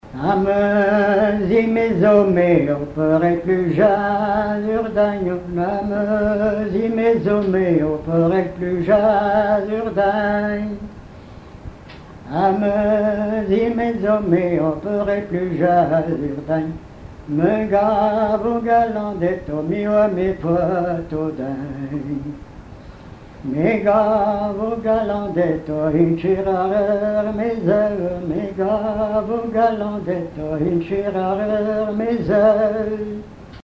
strophique
Chansons traditionnelles
Pièce musicale inédite